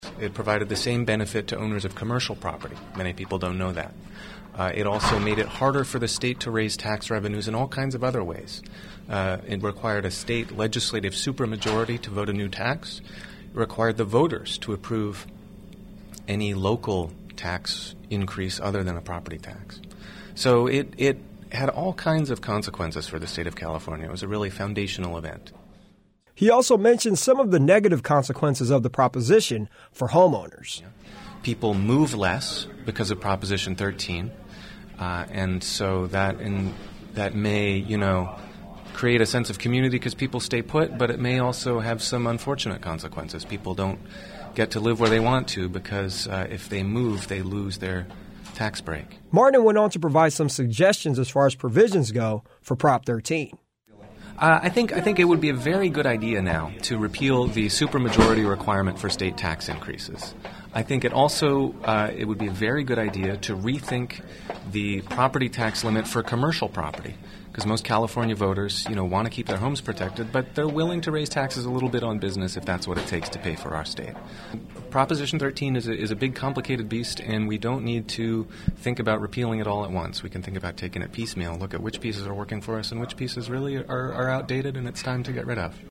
Prop 13 Interview
prop13interview.mp3